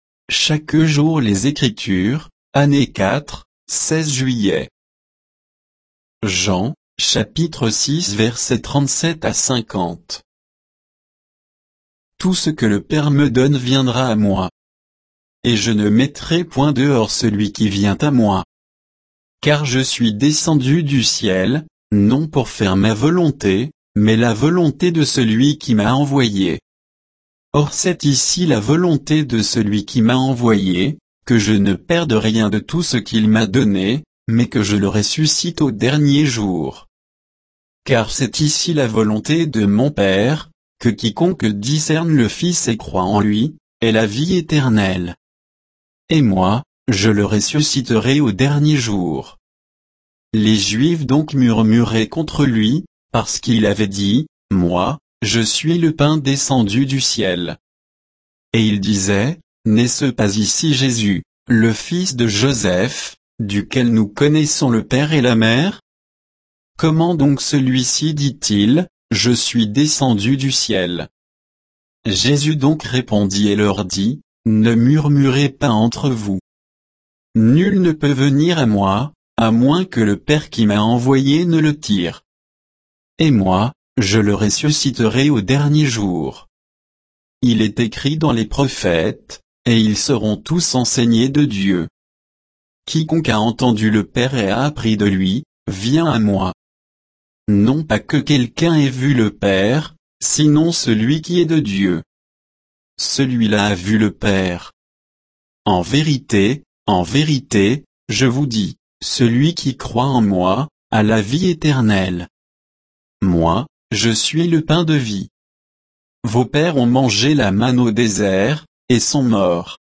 Méditation quoditienne de Chaque jour les Écritures sur Jean 6